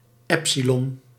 Ääntäminen
Ääntäminen US
IPA : /ˈɛpsɪlɒn/